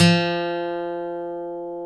Index of /90_sSampleCDs/Roland L-CD701/GTR_Steel String/GTR_ 6 String
GTR 6-STR30T.wav